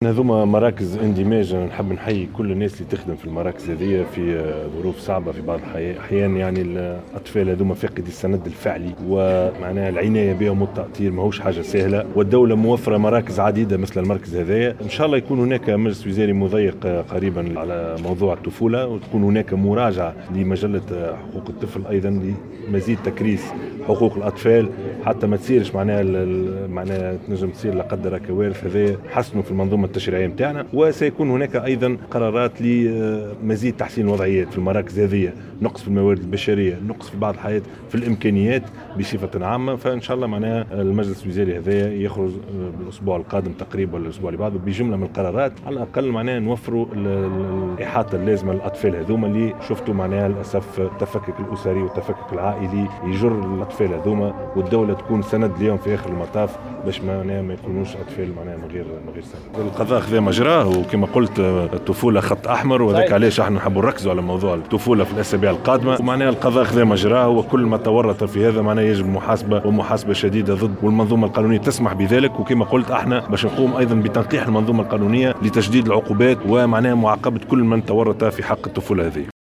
وأشار الشاهد في تصريح اعلامي على هامش زيارته للمركز المندمج للشباب والطفولة بحي الخضراء بالعاصمة، الى تسجيل المراكز المندمجة لرعاية الطفولة لنقص في الامكانيات المادية والموارد البشرية والتجهيزات، مشيرا الى أن المجلس الوزاري المزمع عقده قريبا سيخرج بجملة من الاجراءات من أجل تطوير هذه المراكز وتوفير الموارد البشرية والمادية لها وتحسين مستوى الخدمات التي توفرها للأطفال فاقدي السند.